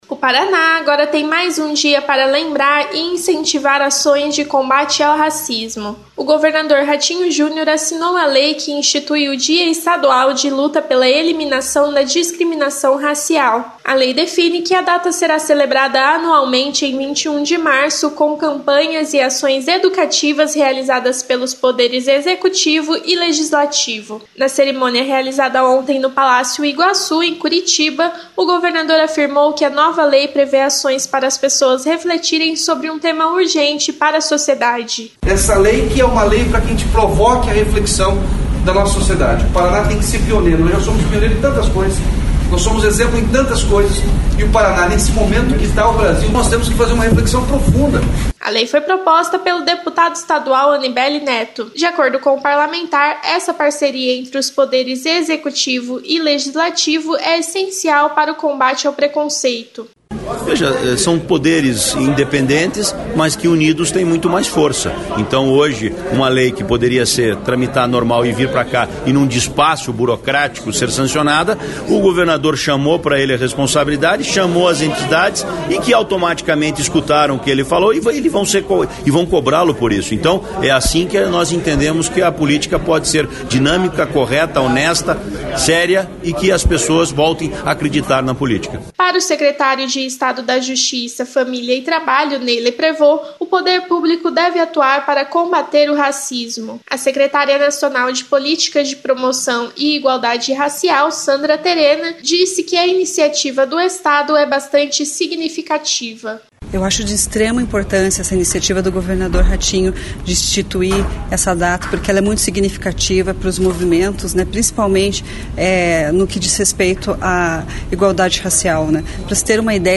Outras informações na programação da Rádio Cultura AM 930